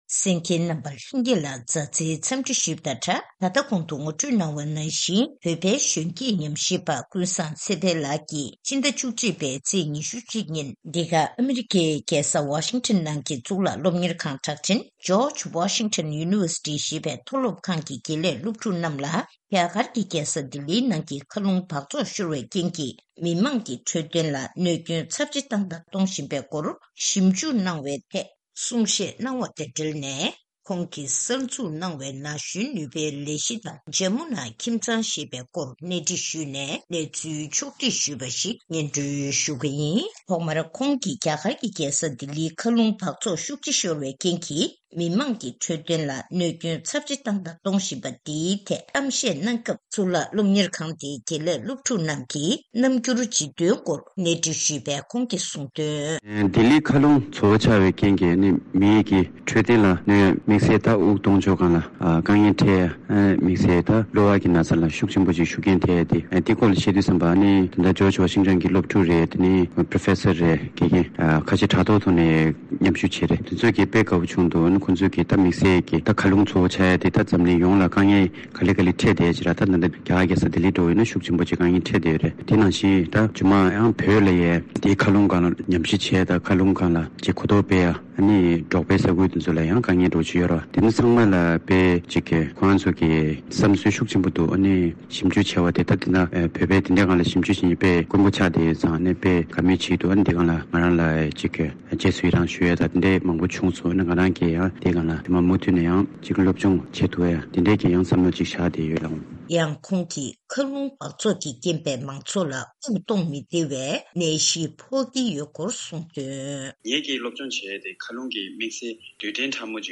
ཐེངས་འདིའི་གནས་འདྲིའི་ལེ་ཚན